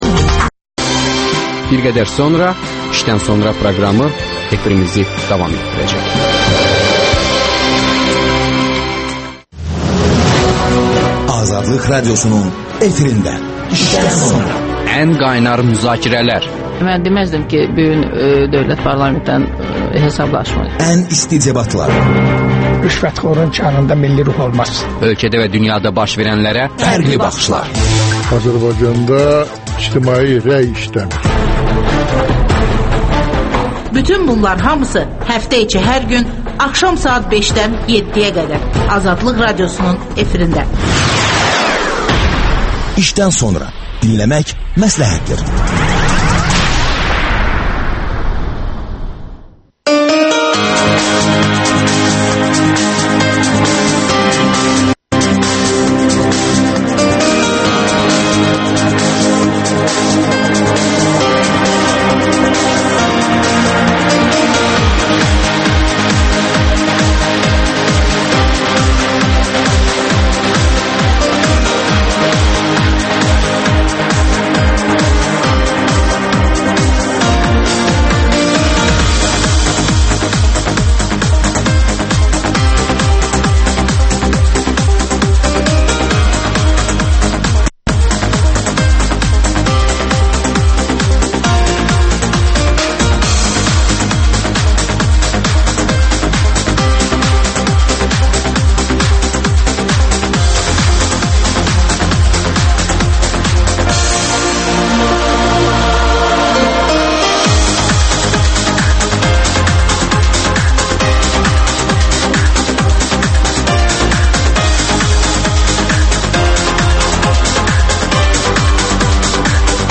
Partiya liderləri canlı efirdə yeni ildən öz gözləntilərini açıqlayırlar.